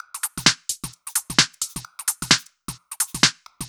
Index of /musicradar/uk-garage-samples/130bpm Lines n Loops/Beats
GA_BeatnPercE130-11.wav